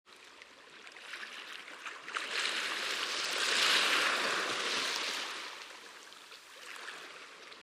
MAREA
MAREA - Tono movil - EFECTOS DE SONIDO
Tonos gratis para tu telefono – NUEVOS EFECTOS DE SONIDO DE AMBIENTE de MAREA
marea.mp3